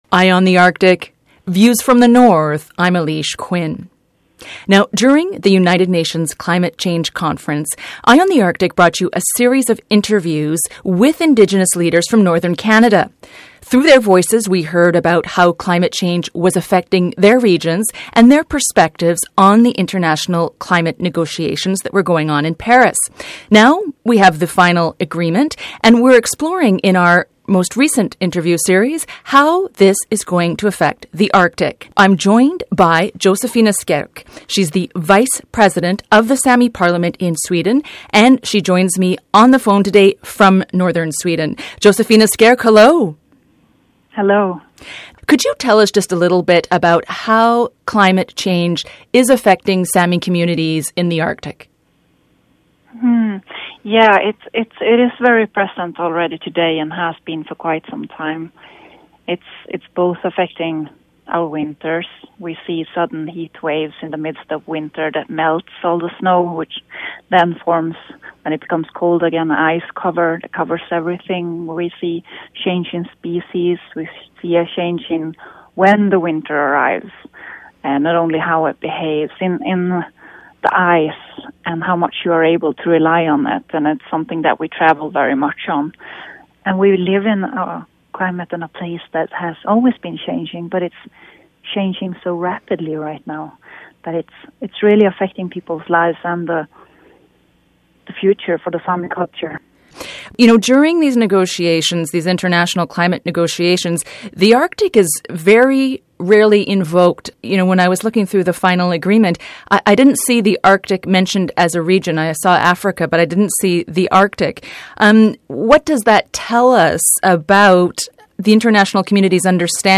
Feature Interview: Josefina Skerk
Listen to our Eye on the Arctic conversation with Josefina Skerk, vice-president of Sweden’s Saami parliament: